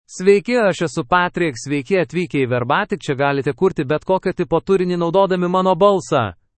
PatrickMale Lithuanian AI voice
Voice sample
Listen to Patrick's male Lithuanian voice.
Male
Patrick delivers clear pronunciation with authentic Lithuania Lithuanian intonation, making your content sound professionally produced.